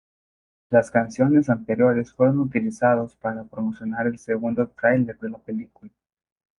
Read more (masculine) trailer (vehicle) (masculine) trailer (preview) Frequency 23k Hyphenated as trái‧ler Pronounced as (IPA) /ˈtɾaileɾ/ Etymology Borrowed from English trailer In summary Borrowed from English trailer.